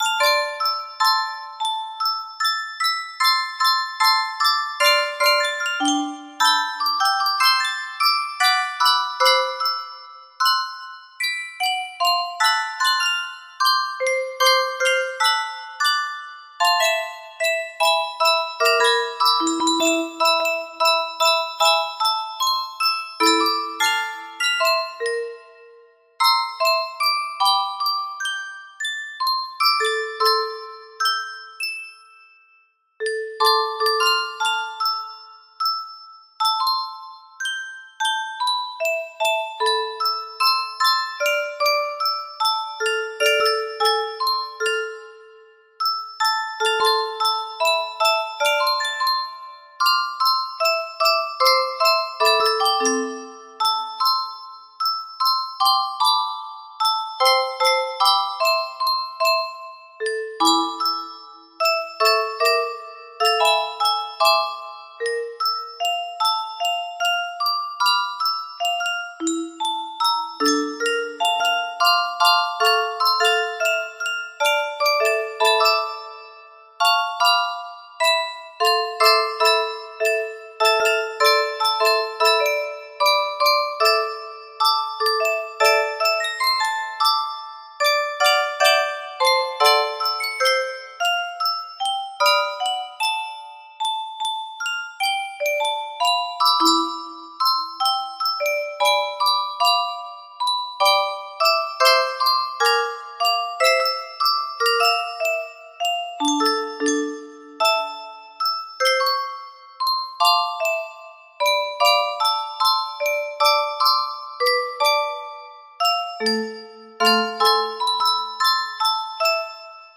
Whispers of Fusion music box melody
Full range 60